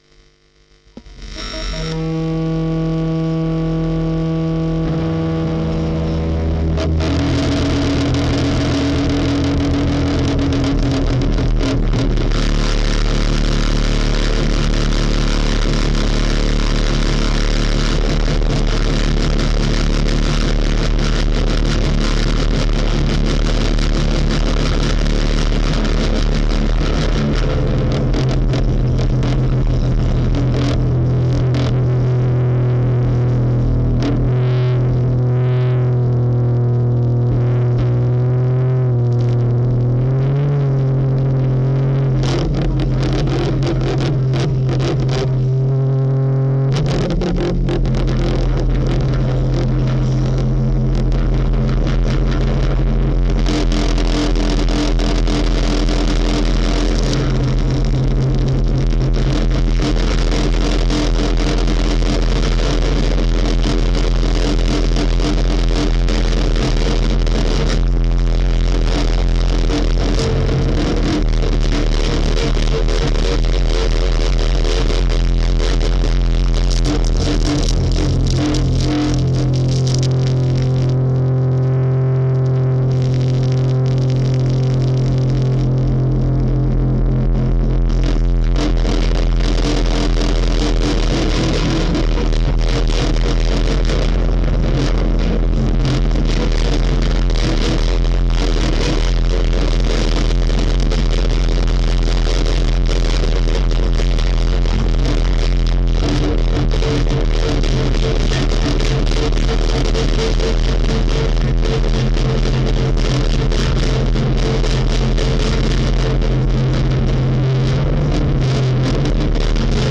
Experimental Music
Music for detuned guitar, e-bow, bottleneck and fuzz added
0015musicfordetunedguitarebowbottleneckandfuzz.mp3